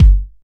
Clean House Steel Kick Drum Sample G Key 771.wav
Royality free kick sound tuned to the G note. Loudest frequency: 110Hz
.WAV .MP3 .OGG 0:00 / 0:01 Type Wav Duration 0:01 Size 36,42 KB Samplerate 44100 Hz Bitdepth 16 Channels Mono Royality free kick sound tuned to the G note.
clean-house-steel-kick-drum-sample-g-key-771-XeT.ogg